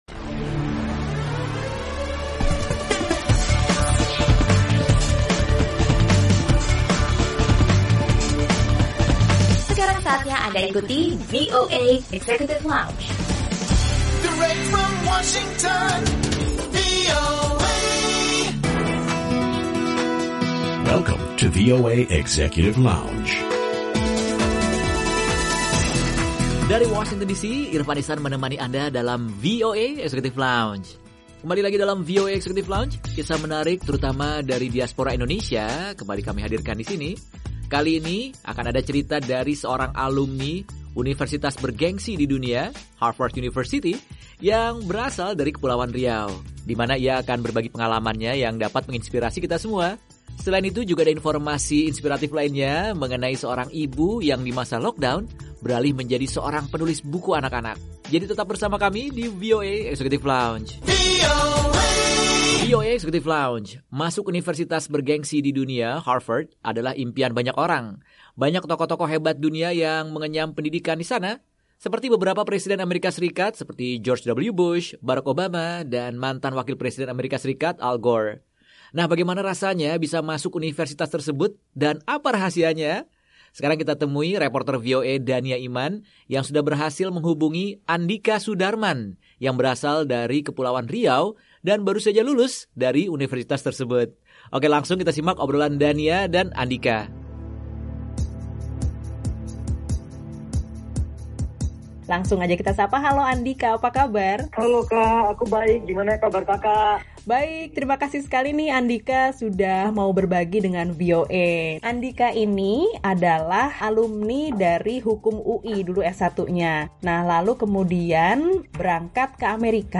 Obrolan bersama seorang alumni universitas Harvard, Massachusets asal Indonesia